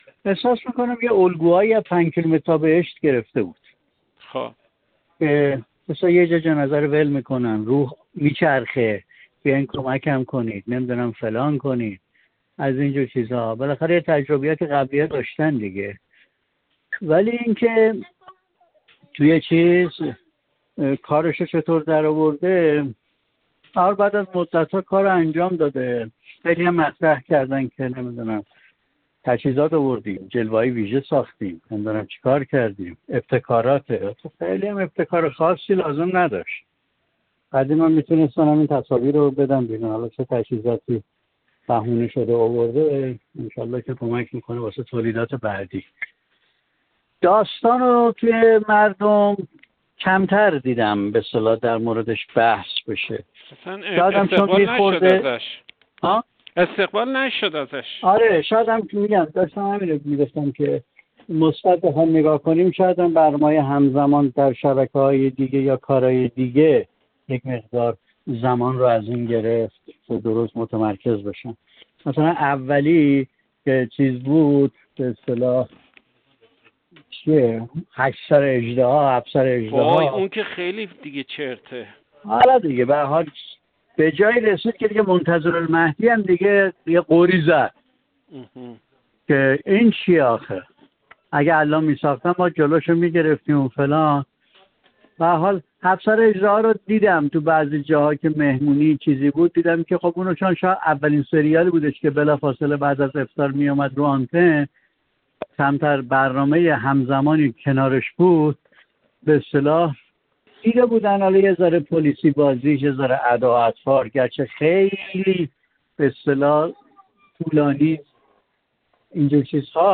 یک کارشناس سینما و تلویزیون گفت: کمیت‌گرایی در سریال‌های مناسبتی ماه رمضان امسال اتفاق بدی برای پر کردن آنتن است و در نهایت روی کیفیت تولیدات، تأثیر منفی خود را دارد.